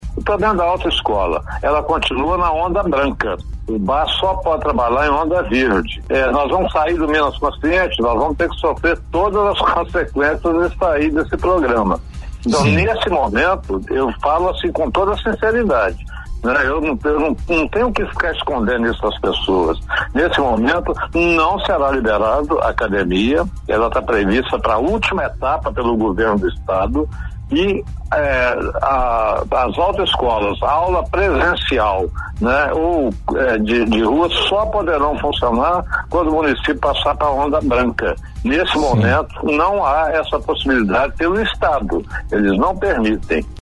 Em entrevista ao Programa Conexão Líder da Rádio Líder(09/07), o administrador público lembrou que agora, Academias e Autoescolas, não irão retornar suas atividades.
Entrevista exibida na Rádio Líder FM Ubá-MG